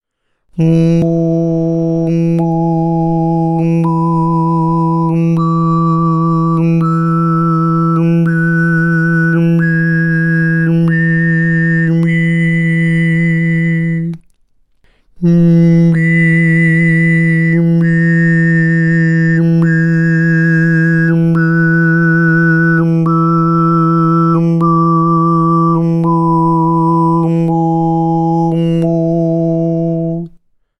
Hörprobe Obertonreihe Nr. 2 gesungen von H4 bis H12 mit Bird-Technik bzw. L-Technik (langsam)
Bei den hier zu hörenden Aufnahmen bleibt der Grundton jeweils stabil auf einer sogenannten Grundton-Frequenz
obertonreihe_h4_bis_h12_bird_slow.mp3